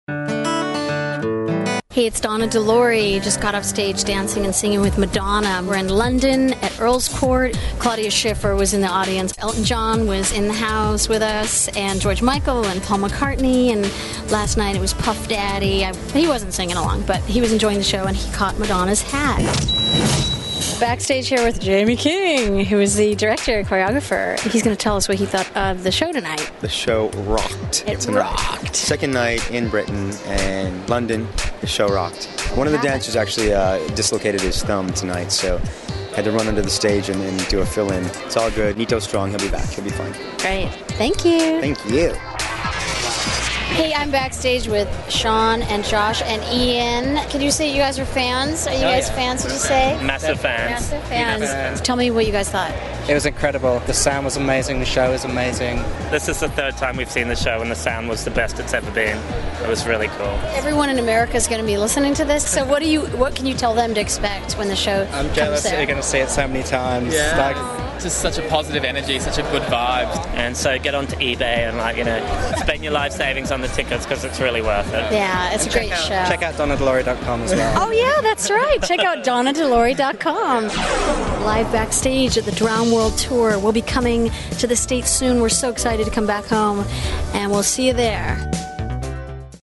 Donna De Lory has been doing backstage reports on the Drowned World tour every Monday for 200 US radio stations.